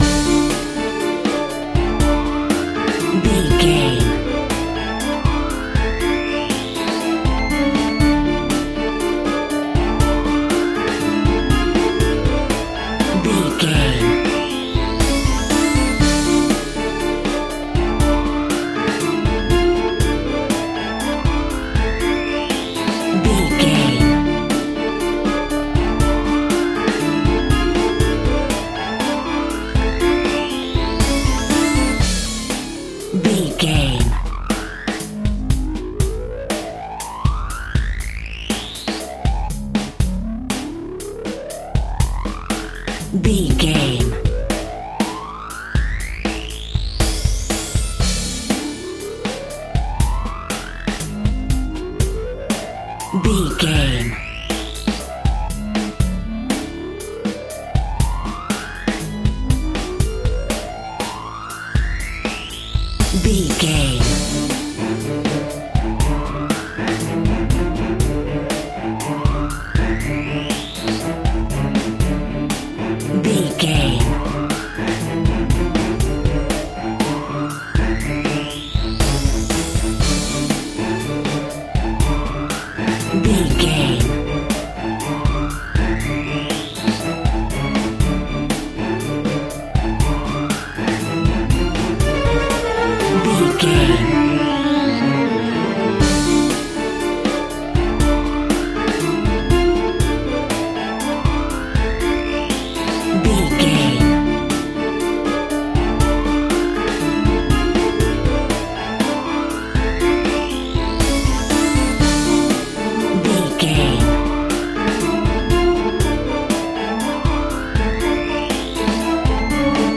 Thriller
Aeolian/Minor
D
Fast
scary
tension
ominous
dark
suspense
dramatic
eerie
strings
synthesiser
drums
piano
electric guitar
ambience
pads